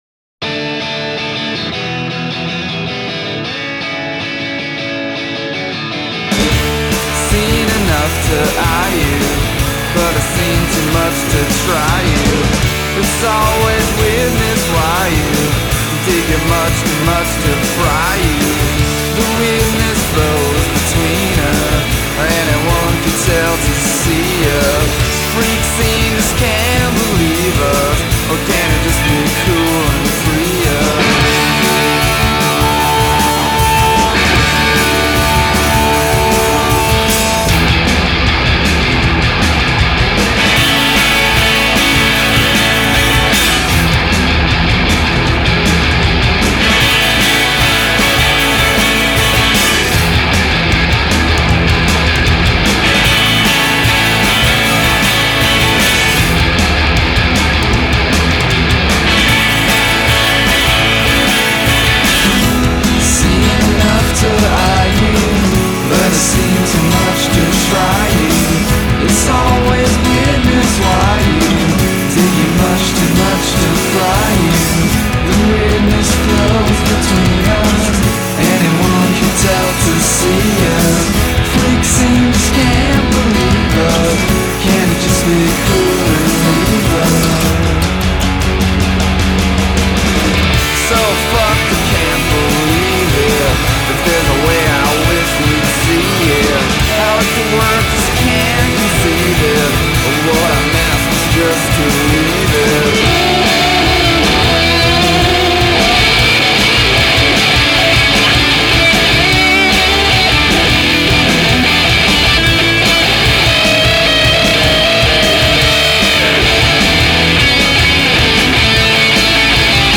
authentic American sound